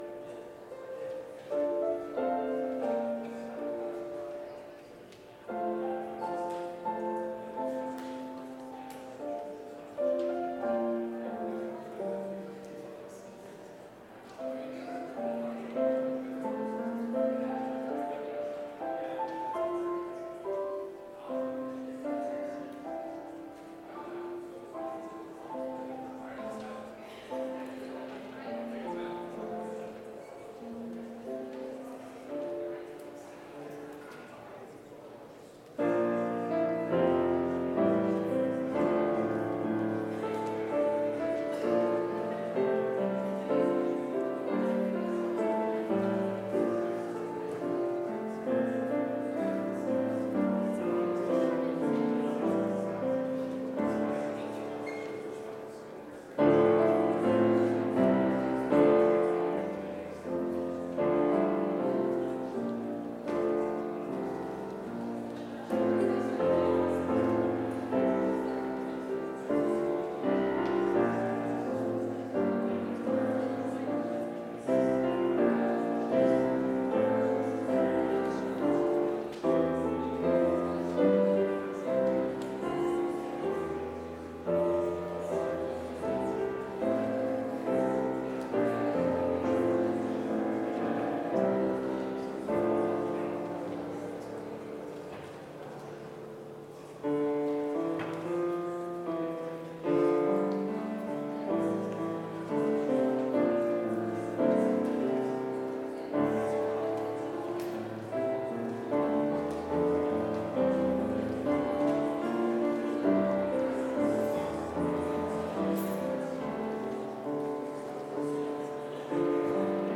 Complete service audio for Chapel - Thursday, November 7, 2024